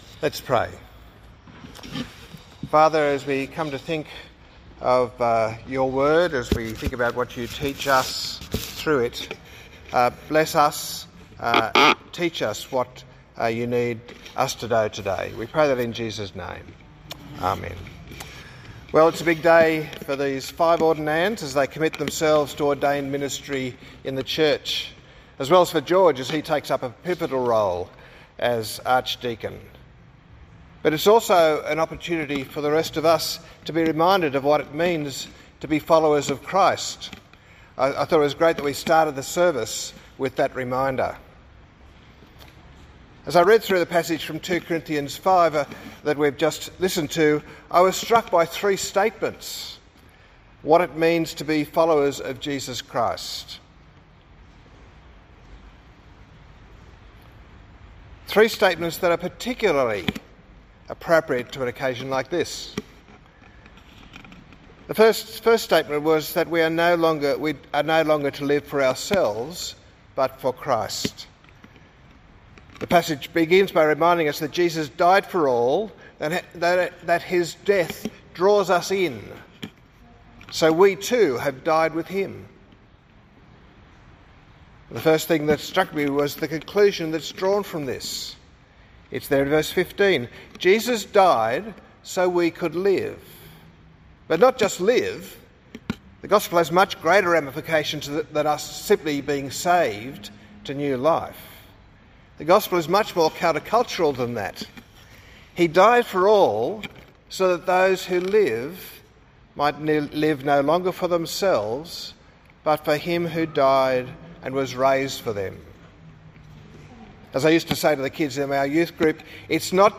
2 Cor 5:14-21 - Ordination Sermon